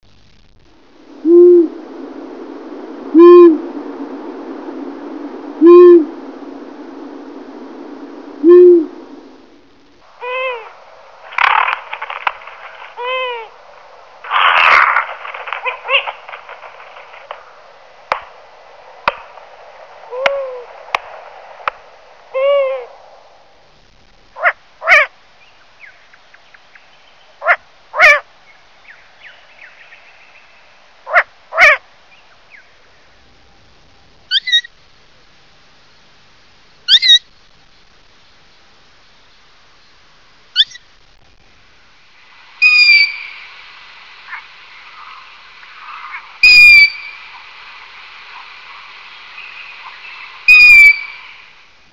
Il gufo comune, il vagabondo delle tenebre.
gufo c.wav